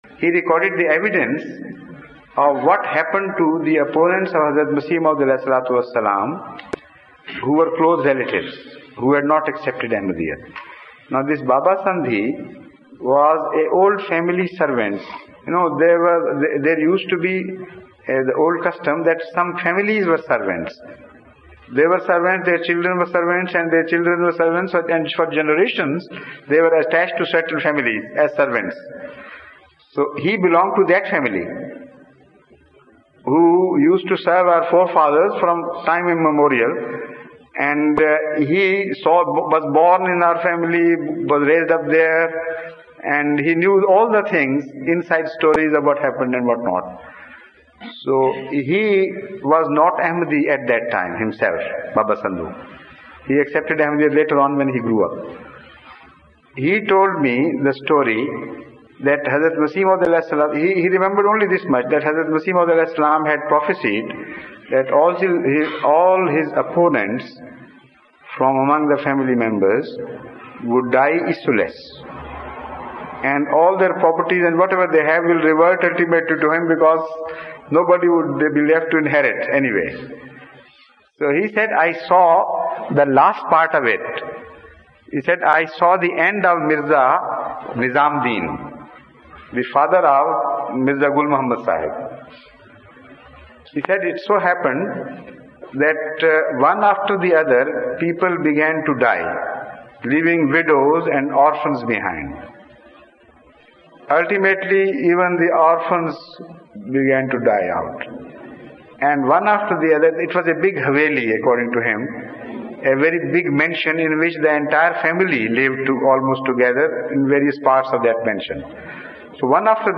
The London Mosque